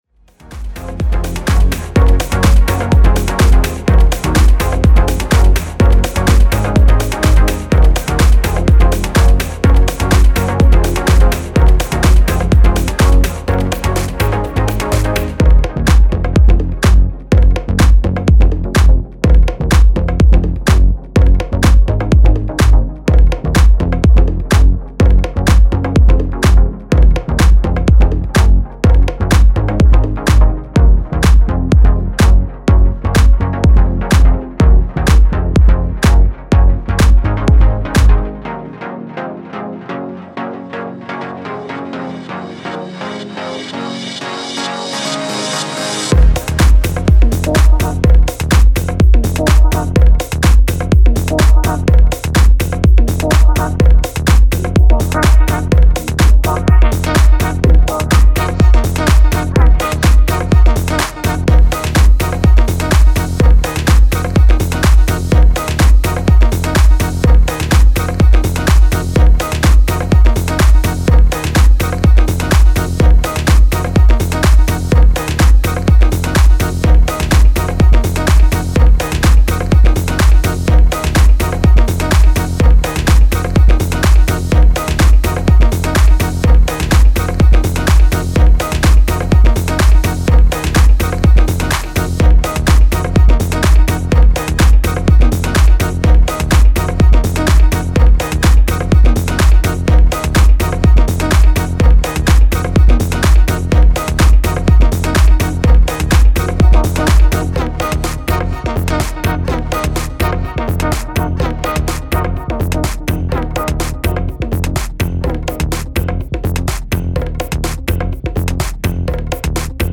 Style: Tech House